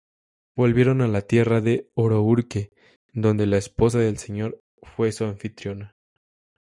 Pronounced as (IPA) /seˈɲoɾ/